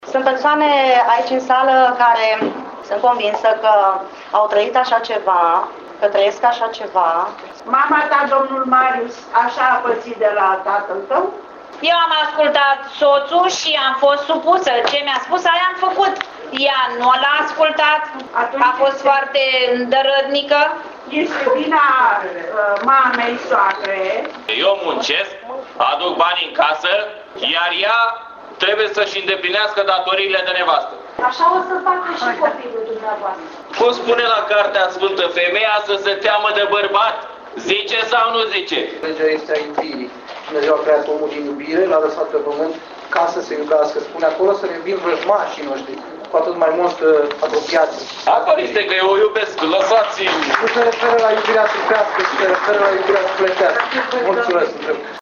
Teatrul forum a fost organizat în perioada celor 16 zile de activism de luptă împotriva violenţei asupra femeii, cuprinse în perioada 25 noiembrie- 10 decembrie, două date marcante la nivel mondial privind drepturile omului. Campania se derulează de 21 de ani în peste 140 de ţări, iar unul dintre obiectivele grupului de lucru de la Ţăndărei este înfiinţarea în oraş a unui centru de ocrotire a victimelor violenţei domestice.